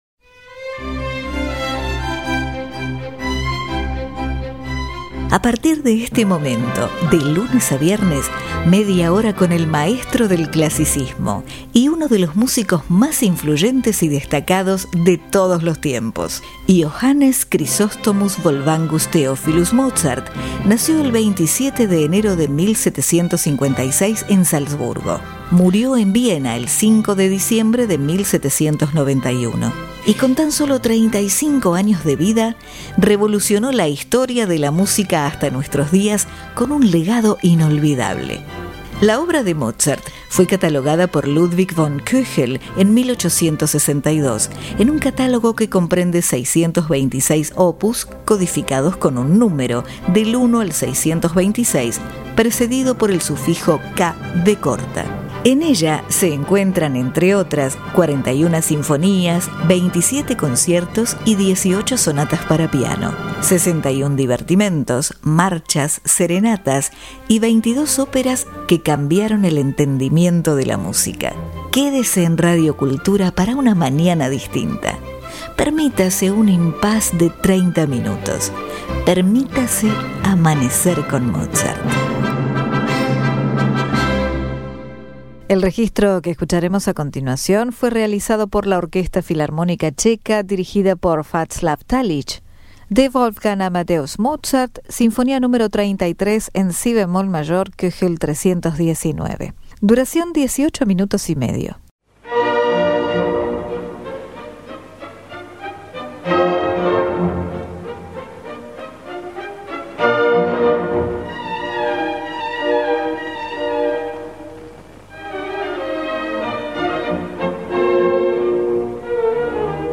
Sinfonía Nº 33 En Si Bemol Mayor K. 319 Orquesta Filarmónica Checa Vaclav Talich
Andras Schiff (Piano)